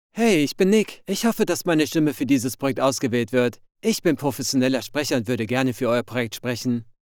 Budweiser commercial voiceover profiles on The Voice Realm.